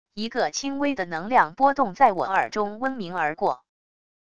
一个轻微的能量波动在我耳中嗡鸣而过wav音频